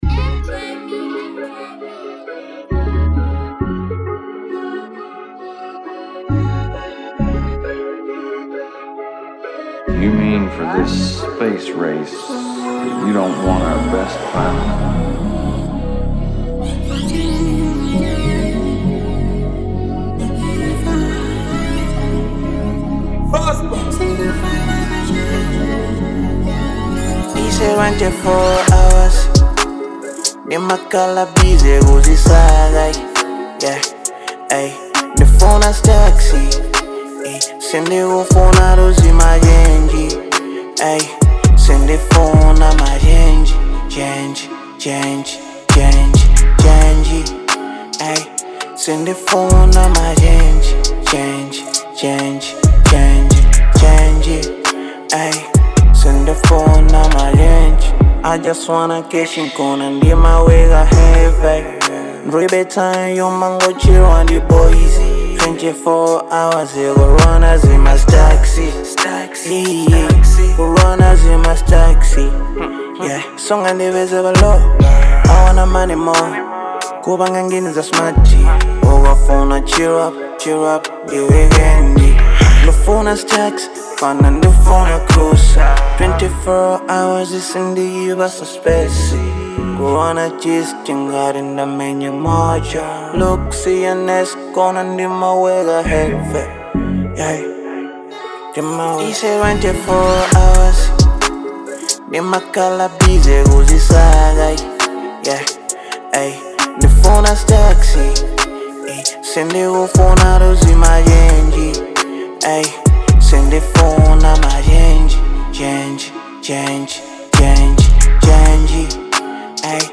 Genre : Hiphop/Trap